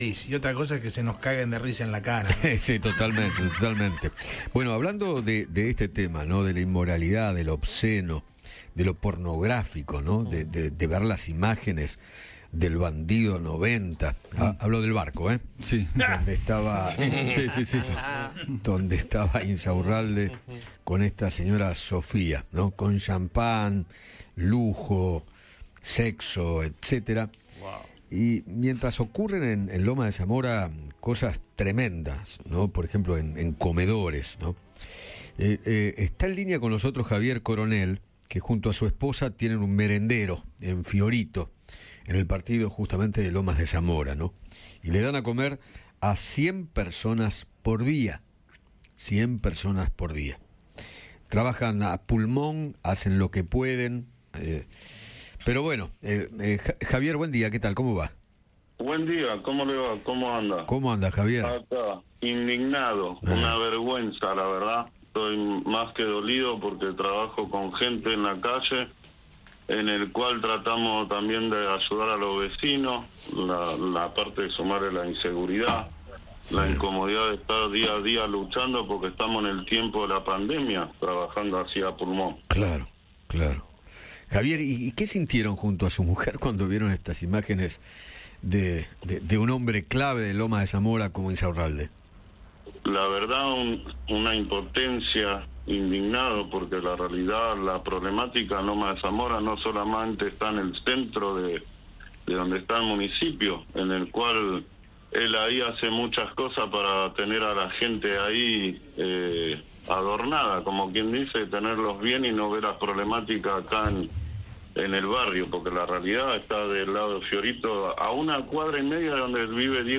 El testimonio del titular de un merendero de Lomas de Zamora: "Insaurralde hace muchas cosas para tener a la gente adornada" - Eduardo Feinmann